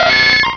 Cri de Nidorino dans Pokémon Rubis et Saphir.